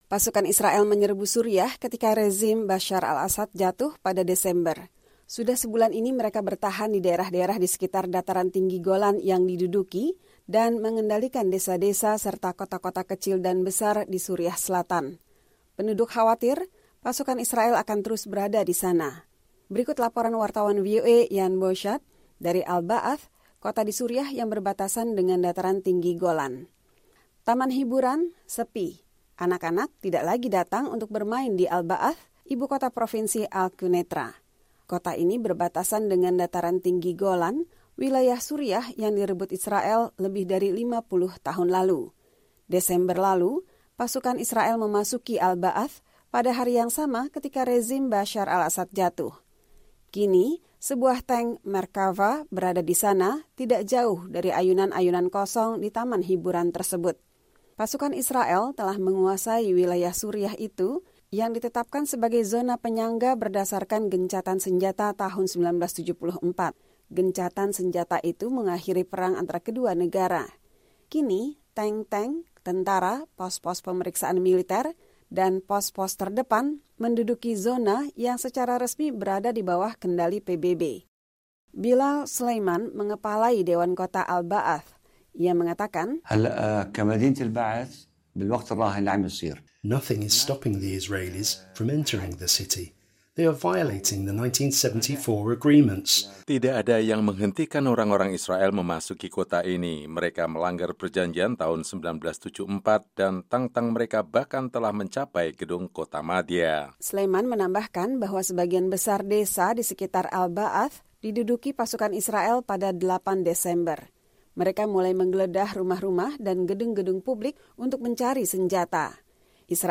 Berikut laporan wartawan VOA